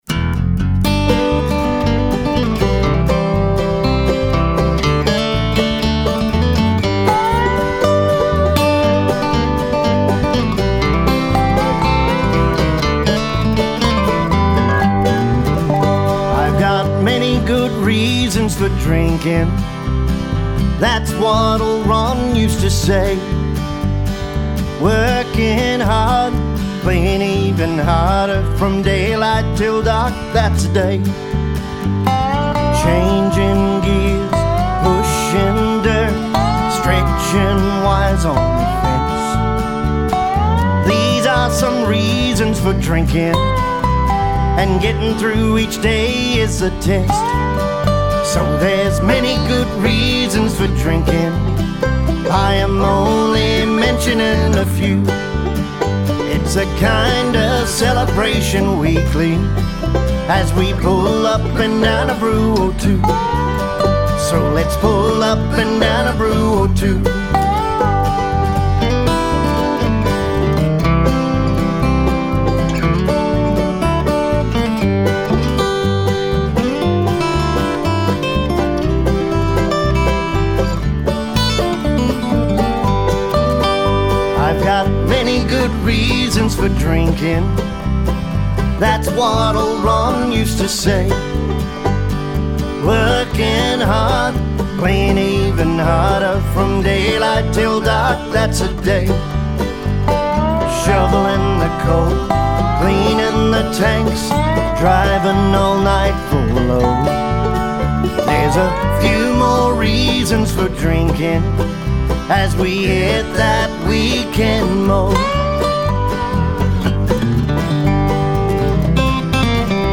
bluegrass injected and inspired track